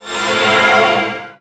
Magic_SpellShield03.wav